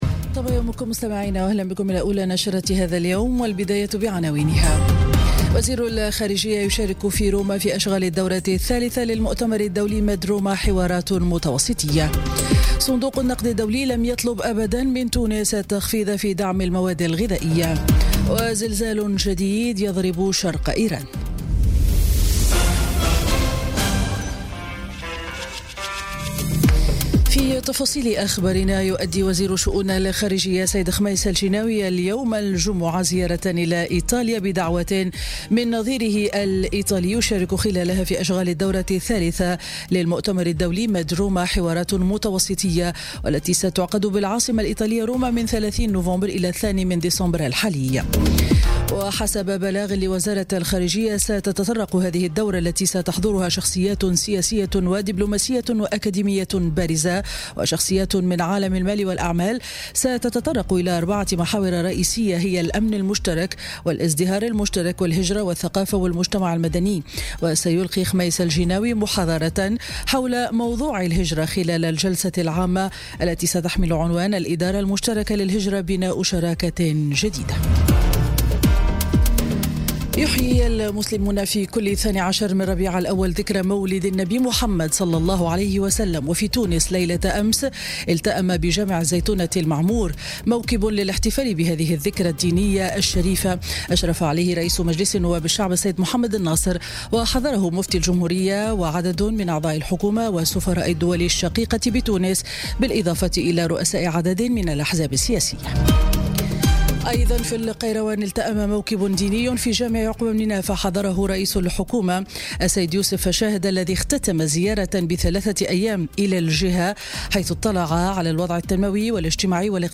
نشرة أخبار السابعة صباحا ليوم الجمعة 1 ديسمبر 2017